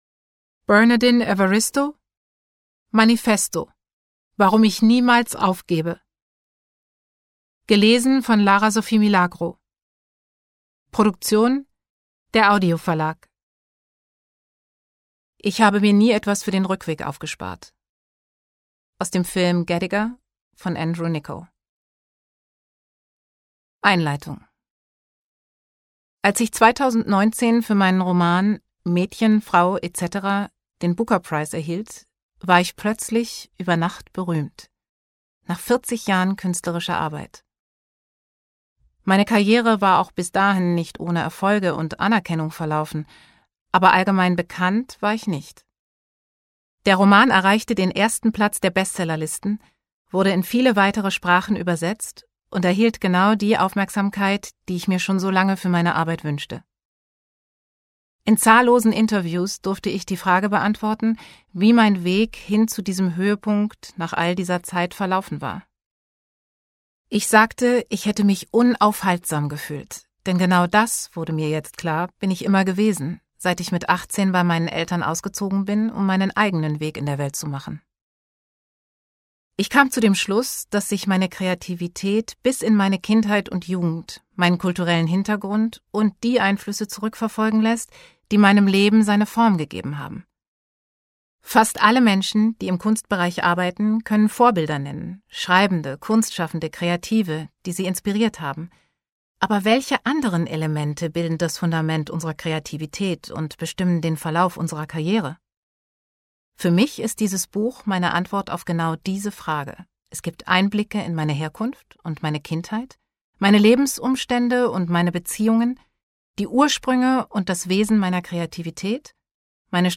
2022 | Ungekürzte Lesung